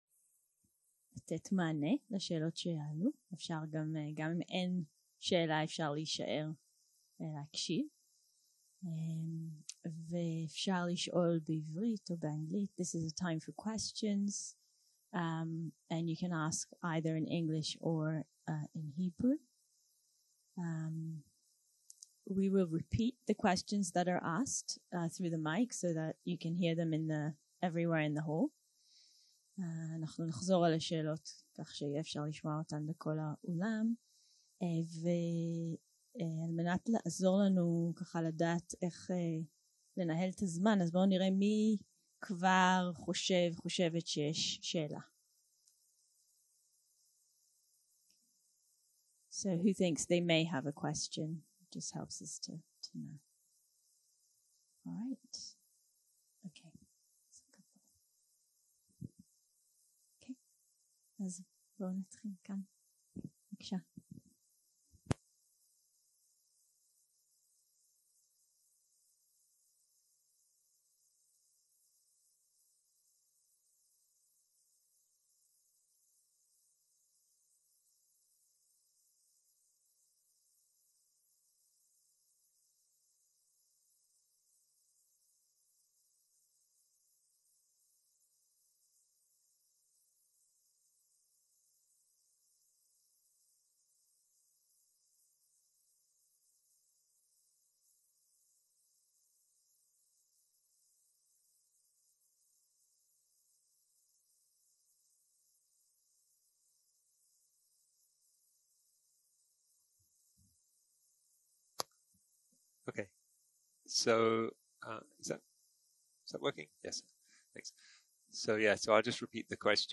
יום 2 - הקלטה 4 - בוקר - שאלות ותשובות
סוג ההקלטה: שאלות ותשובות
ריטריט ראש השנה בנושא מטהה וריקות